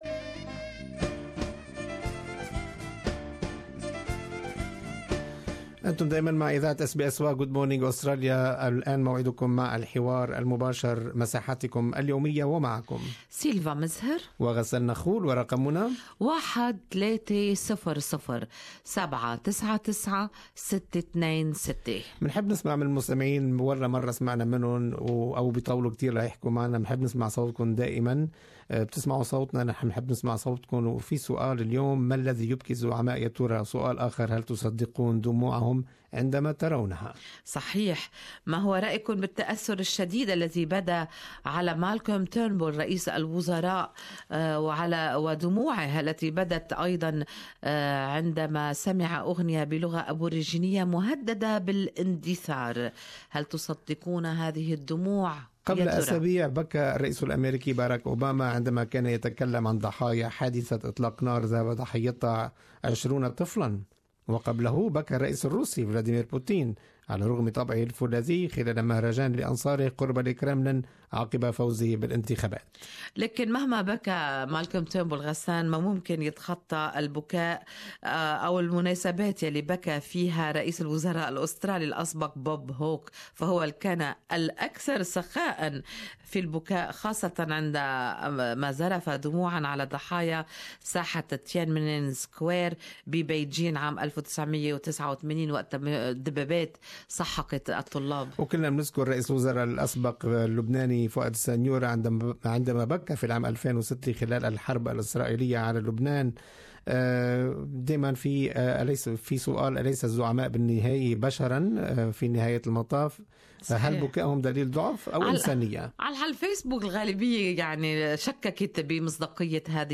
Talkback segment